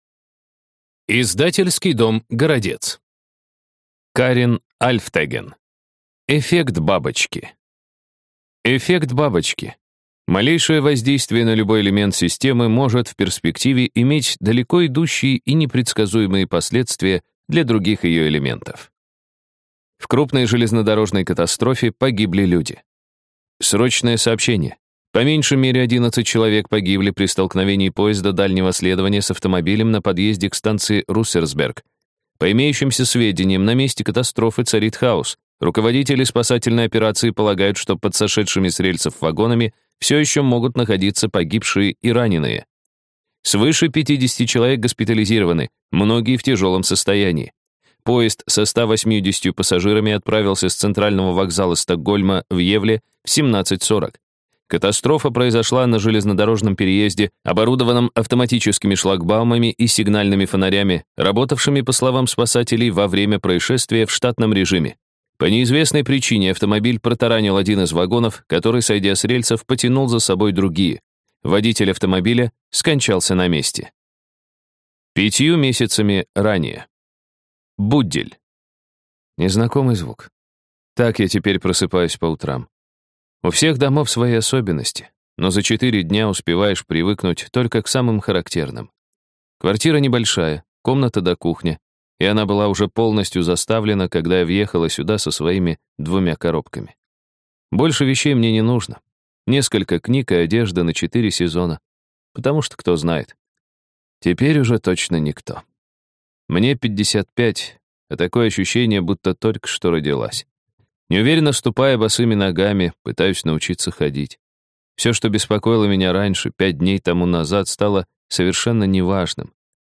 Аудиокнига Эффект бабочки | Библиотека аудиокниг